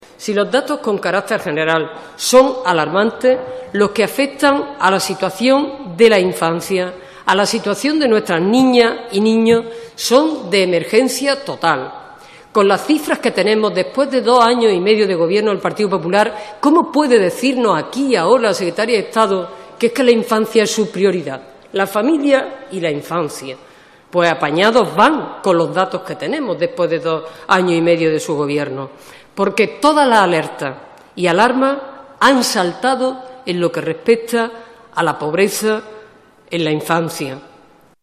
Fragmento de la intervención de Rosa Aguilar en la comparecencia de la secretaria de estado de servicios sociales e igualdad 24/06/2014